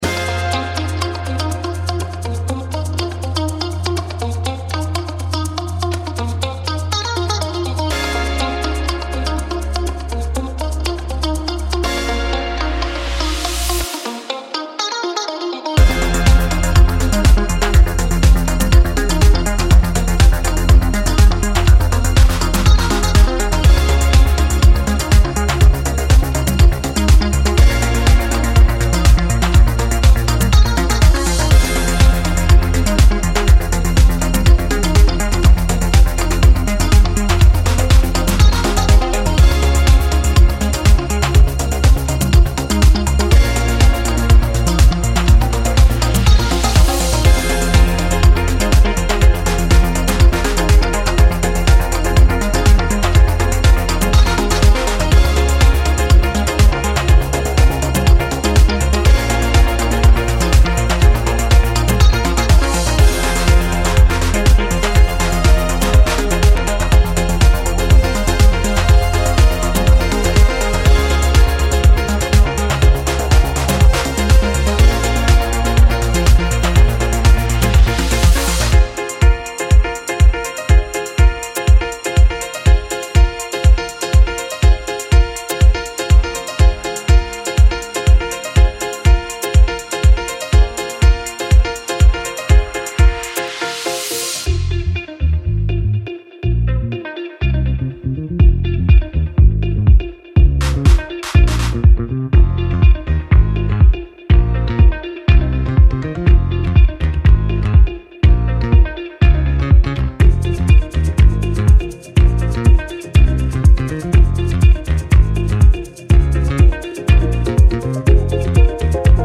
a shimmering Balearic bliss for sunset sessions